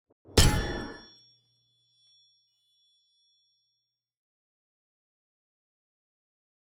stun.wav